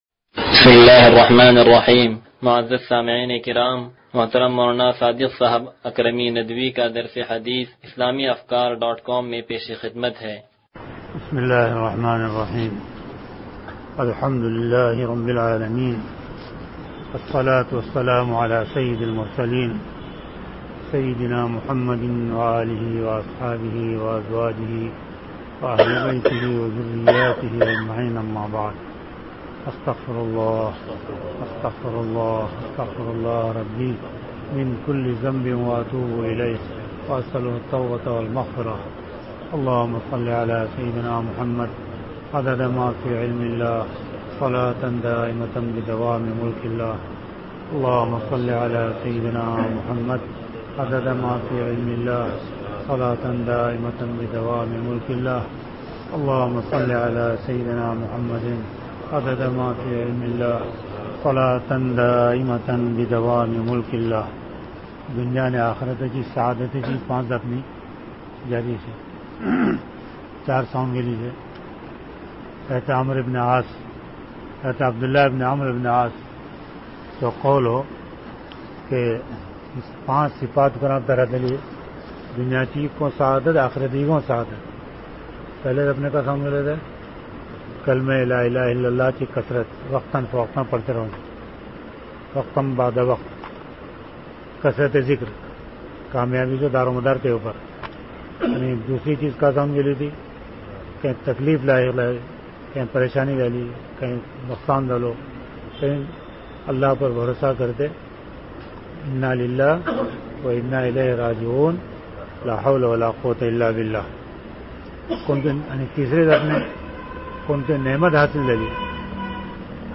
درس حدیث نمبر 0115
درس-حدیث-نمبر-0115.mp3